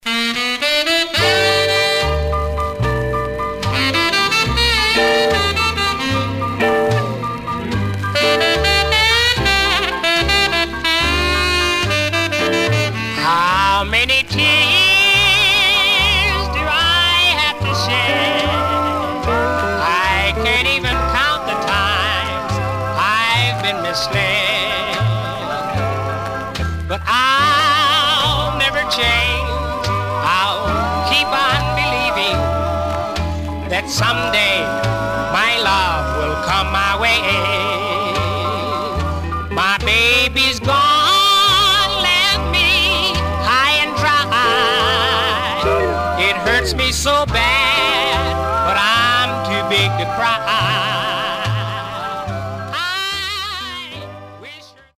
Surface noise/wear
Mono
Male Black Group Condition